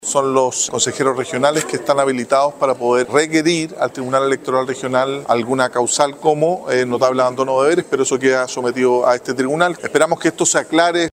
En la misma línea, el diputado Raúl Leiva (PS) recordó que son los consejos regionales quienes tienen las facultades para requerir al Tribunal Electoral Regional, por lo que es necesario esperar el fin de la indagatoria y establecer así las responsabilidades.